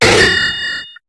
Cri de Dinoclier dans Pokémon HOME.